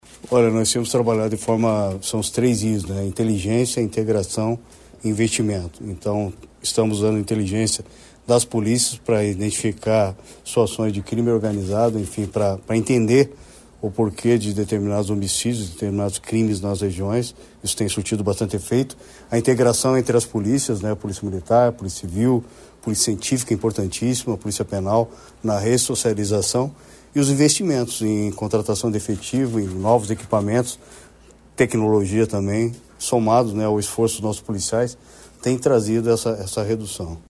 Sonora do secretário da Segurança Pública, Hudson Teixeira, sobre a queda no número de homcídios dolosos no Paraná nos nove primeiros meses de 2025